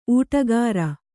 ♪ ūṭagāra